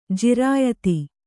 ♪ jirāyata